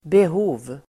Uttal: [beh'o:v]